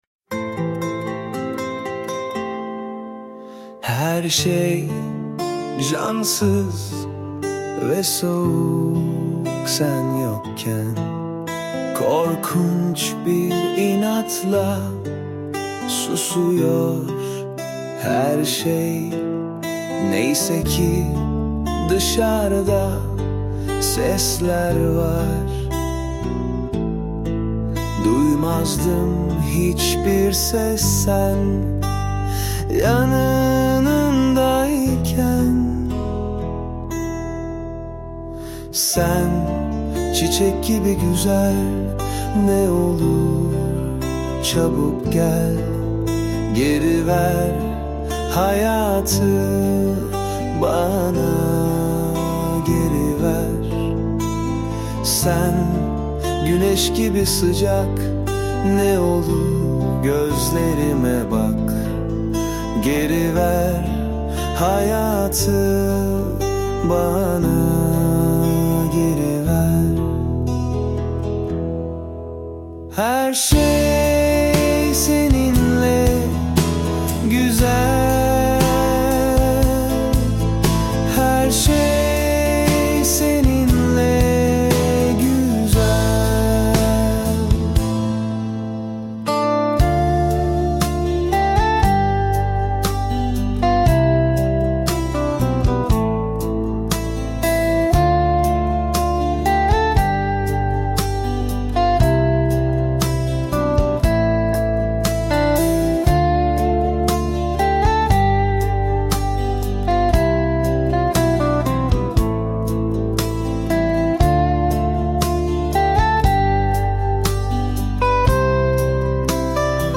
Tür : Akdeniz, Pop, Slow Rock